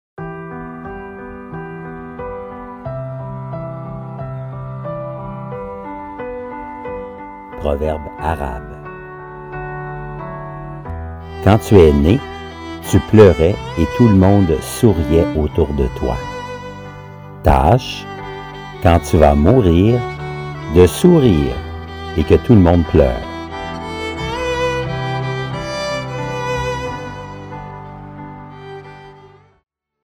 Musique de fond; Le canon de Pachelbel ( violons en 432 htz )
( la qualité sonore est variable… )